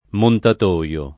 [ montat 1L o ]